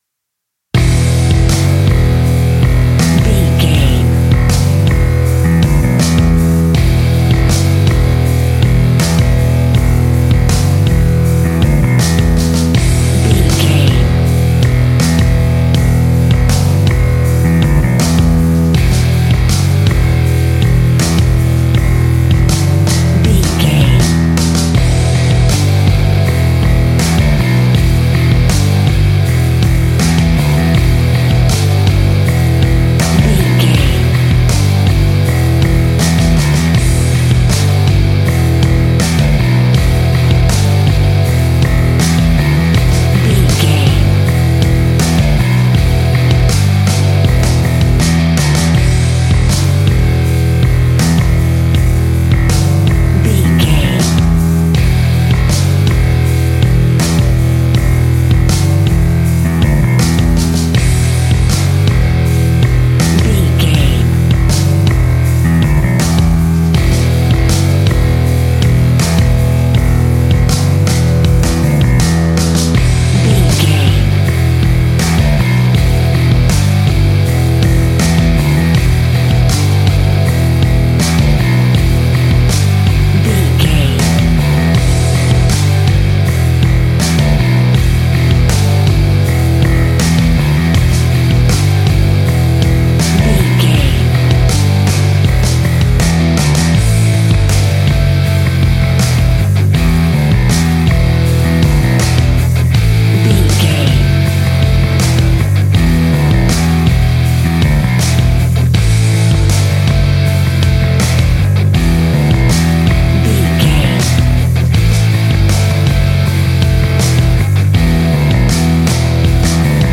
Epic / Action
Aeolian/Minor
hard rock
heavy metal
blues rock
distortion
rock guitars
Rock Bass
Rock Drums
heavy drums
distorted guitars
hammond organ